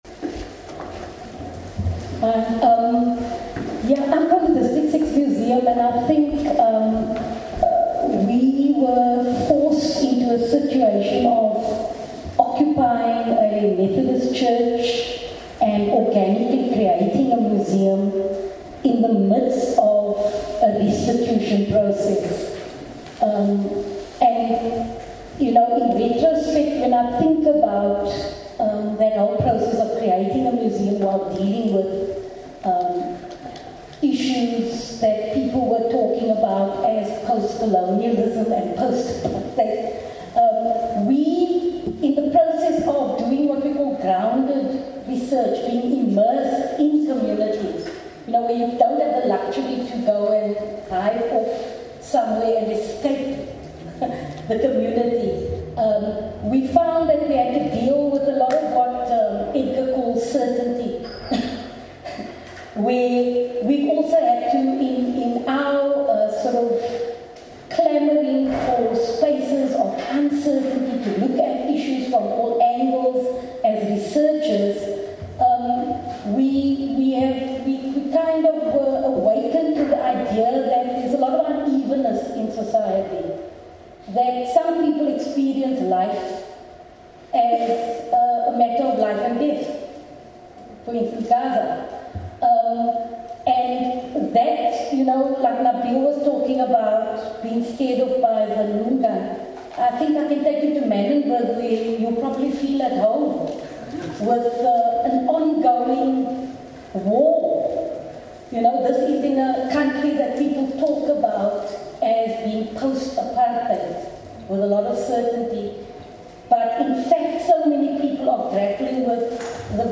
audience homecoming centre, district six | Landwalks Sound Archive / الحق في الأرض: الأرشيف الصوتي